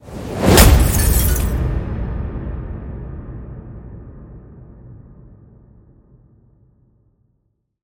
На этой странице собраны разнообразные звуки трейлеров: от эпичных оркестровых композиций до резких динамических переходов.
7. Всплывают титры и трескается стекло